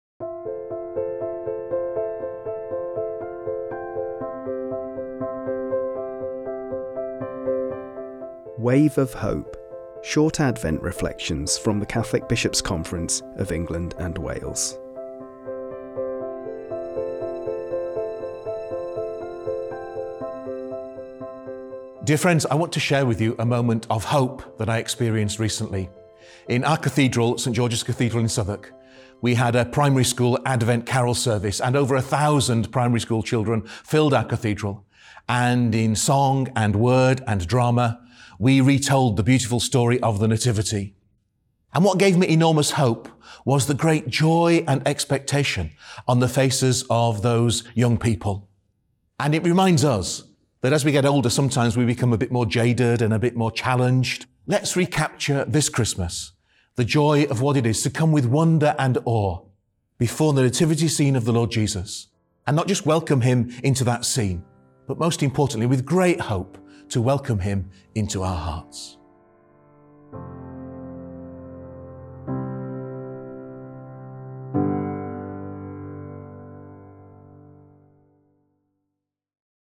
This Wave of Hope reflection for Advent comes from Archbishop John Wilson, Archbishop of Southwark.